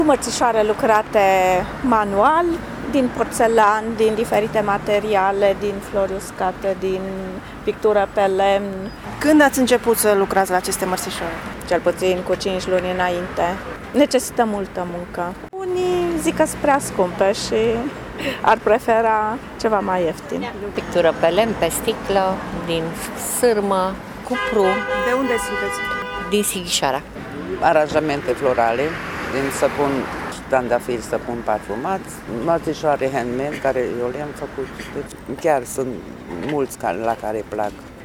În centrul Târgu Mureșului și-au găsit loc și producători autohtoni de mărțișoare, care au început munca încă din iarnă, pentru a veni cu ceva nou și deosebit: